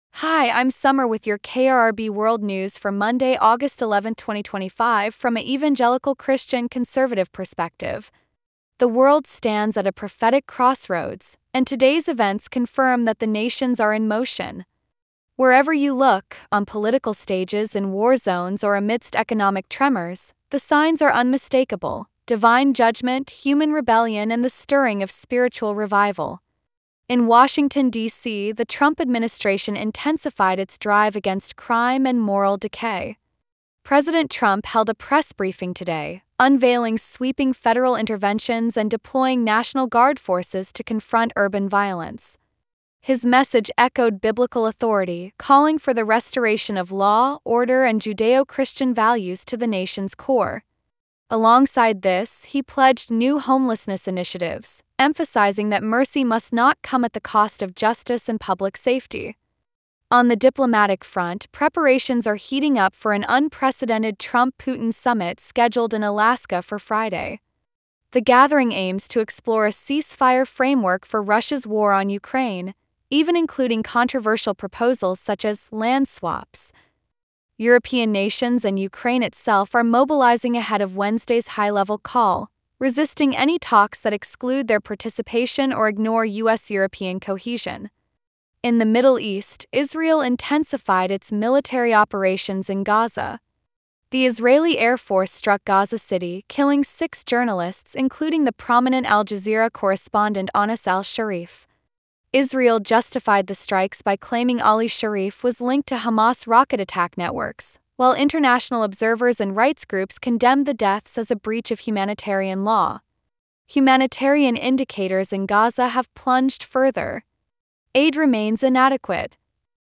KRRB Revelation Radio — World News Report Monday, August 11, 2025